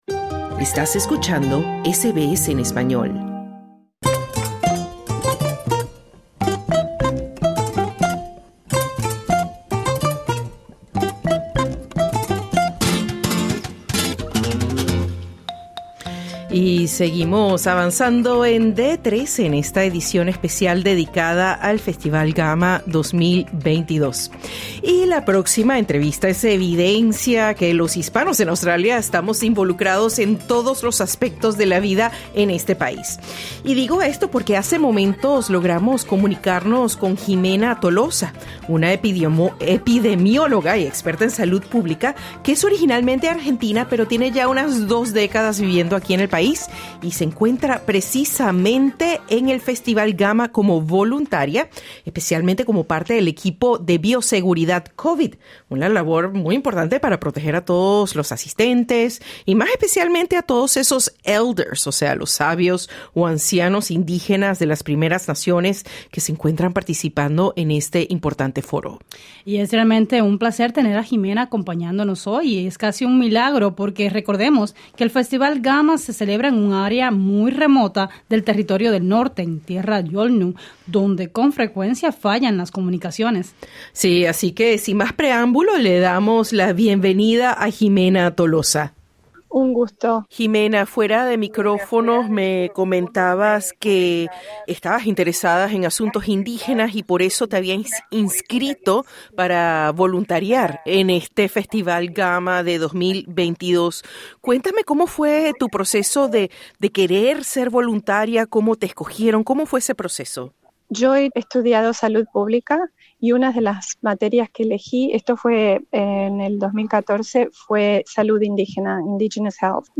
cuenta a SBS Spanish desde el lugar cómo se vivió in situ la emoción de ese y otros importantes momentos, como el fallecimiento del cantautor aborigen Archie Roach.